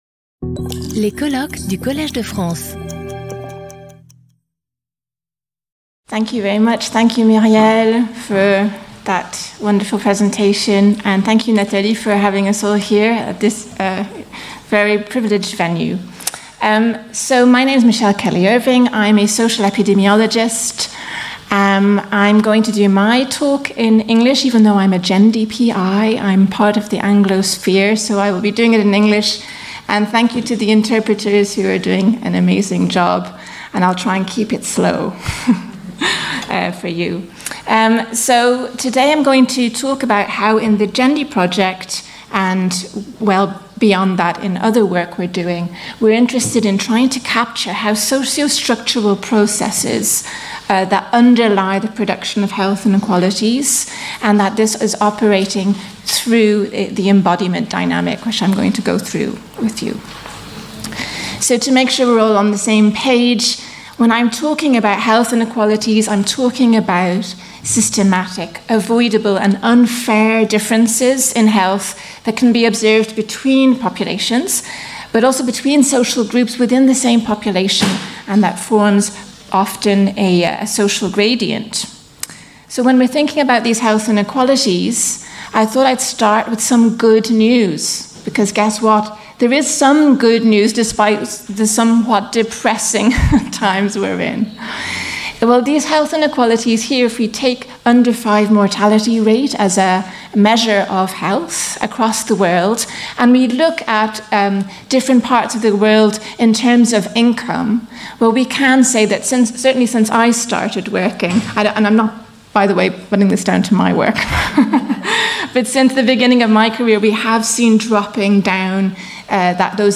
Sauter le player vidéo Youtube Écouter l'audio Télécharger l'audio Lecture audio Cette vidéo est proposée dans une version doublée en français.